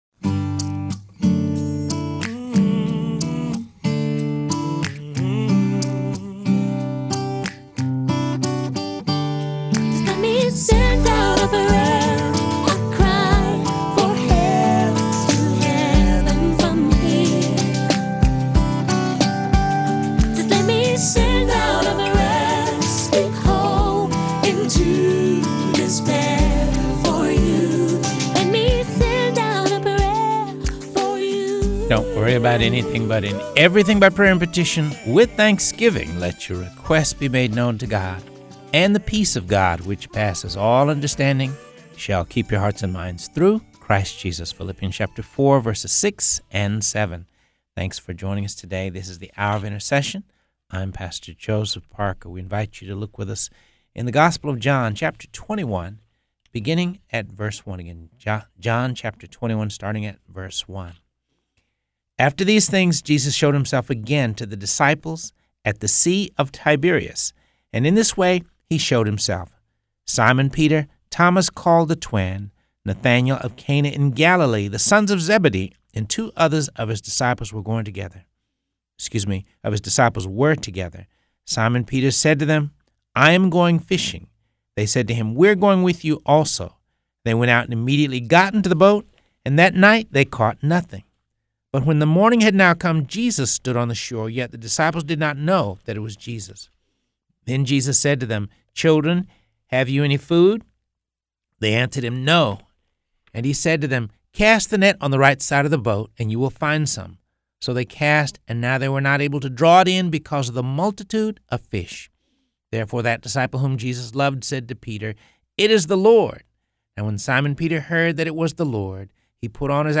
reading through the Bible